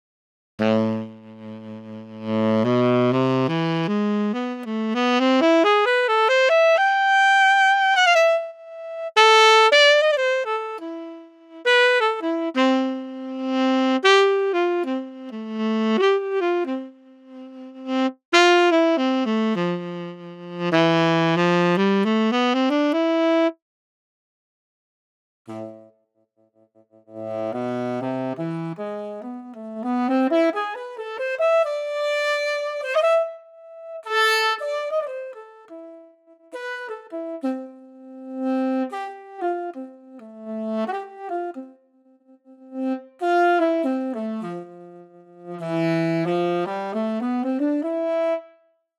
основная проблема аароновских деревянных - это весьма ощутимый фленжер
SWAM vs IW Tenor Sax comparision.mp3